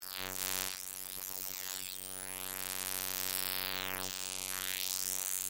Spectrofy is a simple image to audio converter.